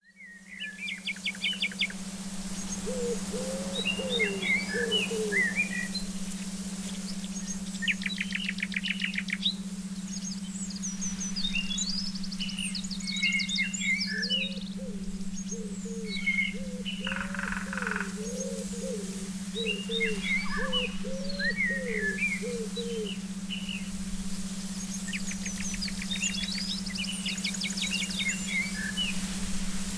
Beautiful nature scenes for relaxing.